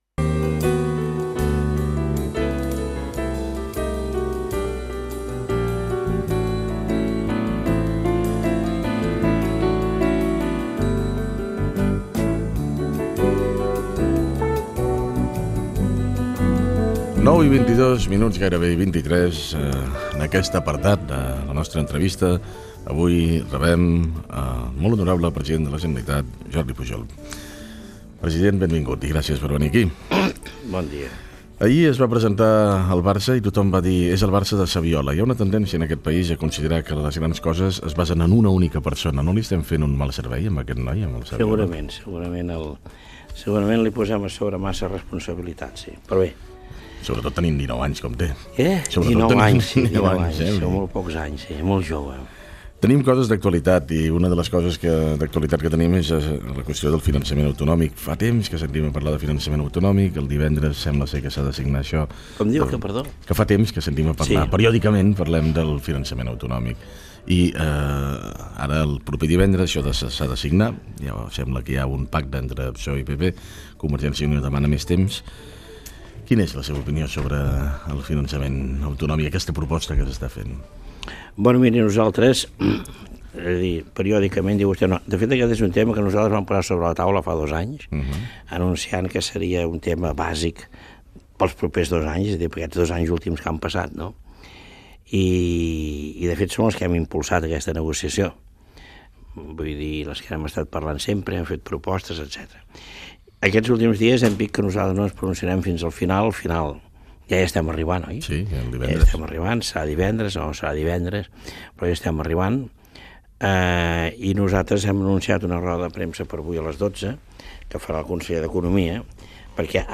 Fragment d'una entrevista al president de la Generalitat Jordi Pujol.
Info-entreteniment